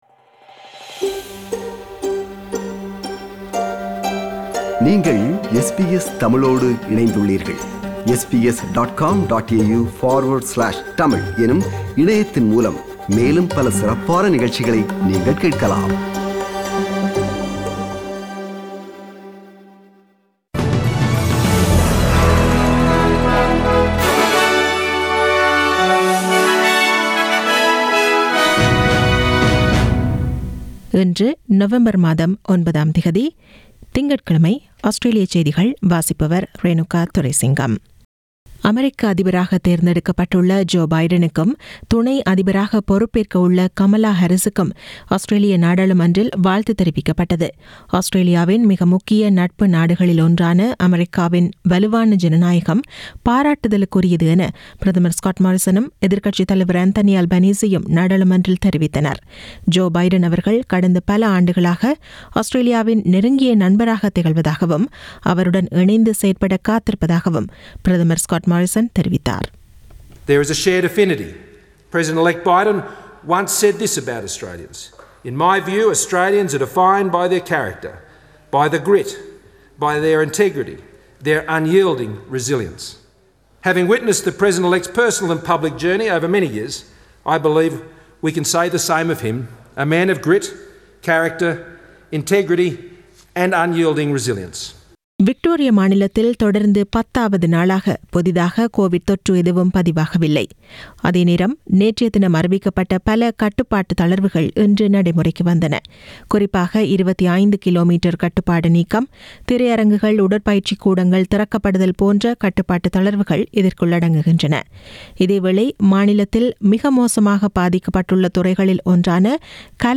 Australian news bulletin for Monday 09 November 2020.